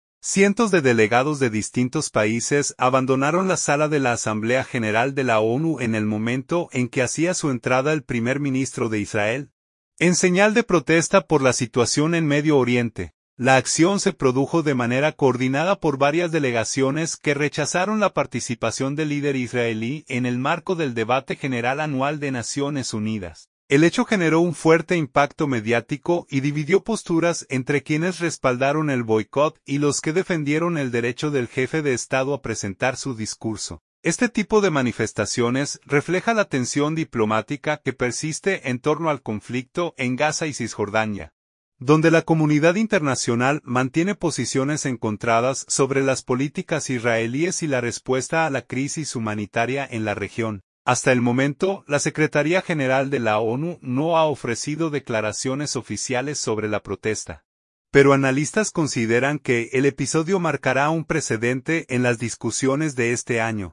Nueva York. – Cientos de delegados de distintos países abandonaron la sala de la Asamblea General de la ONU en el momento en que hacía su entrada el primer ministro de Israel, en señal de protesta por la situación en Medio Oriente.